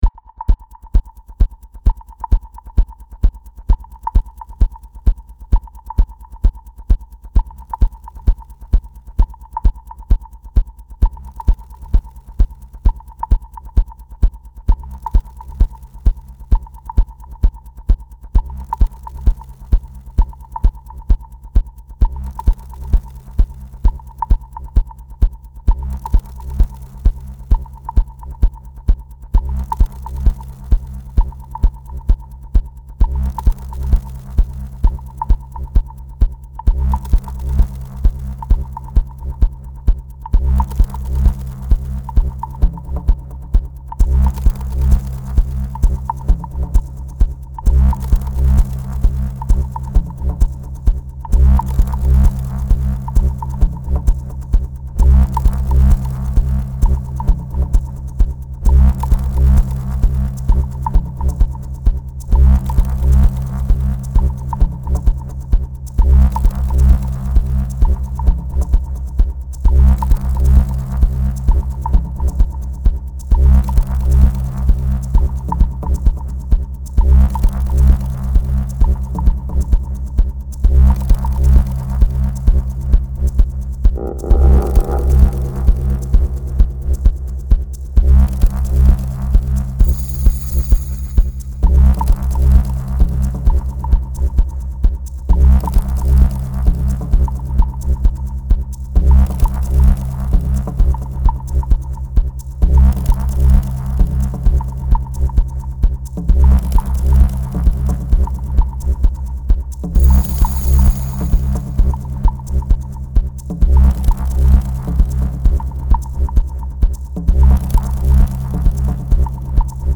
Musikstil: Techno